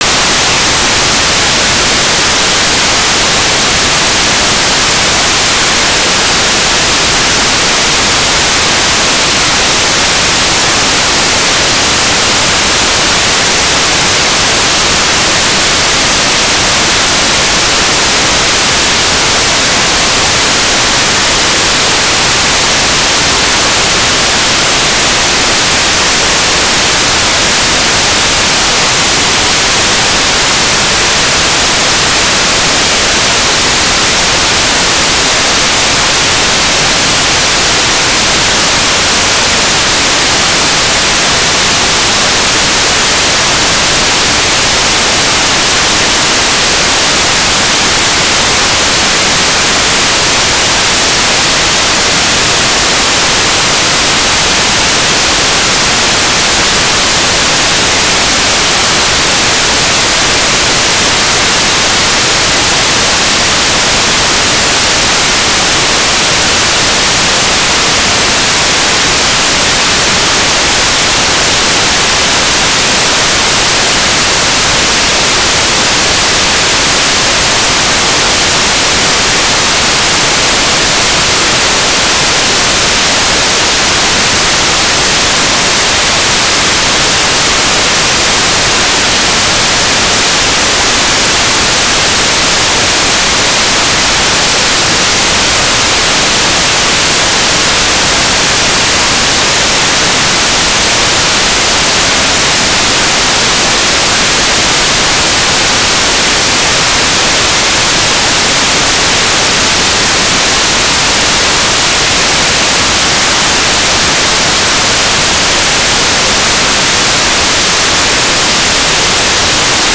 "station_name": "Mogielnica - VHF",
"transmitter_description": "V/V 1k2 AFSK Digipeater",
"transmitter_mode": "AFSK",